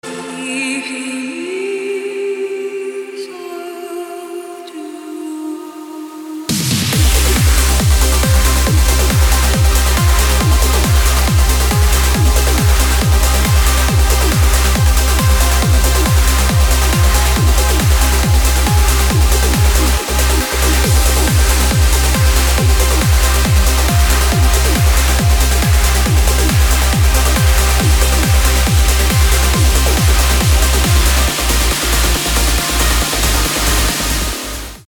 • Качество: 320, Stereo
громкие
dance
Electronic
Жанр: Trance, Progressive Trance, Uplifting Trance.